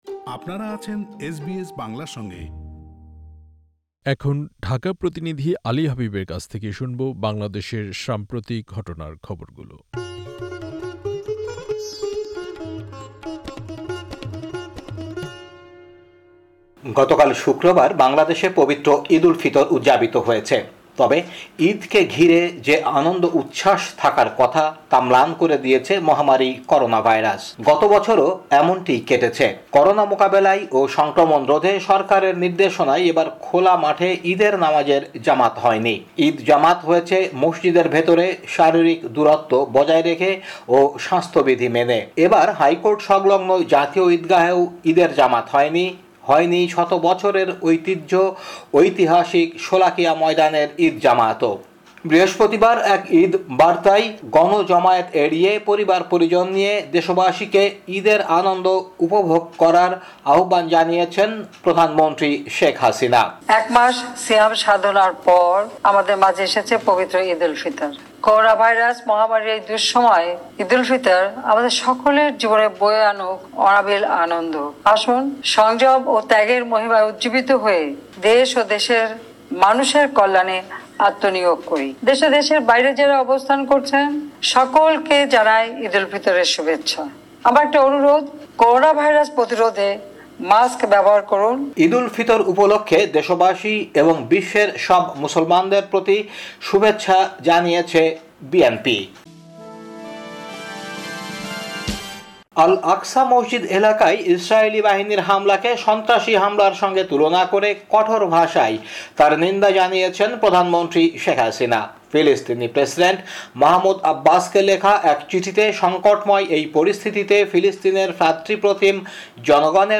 বাংলাদেশের সাম্প্রতিক ঘটনার খবর, ১৫ মে, ২০২১